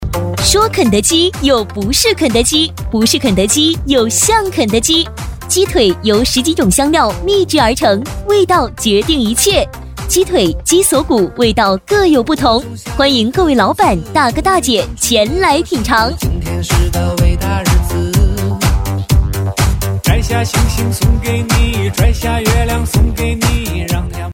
【促销】美食食品女37-激情
【促销】美食食品女37-激情.mp3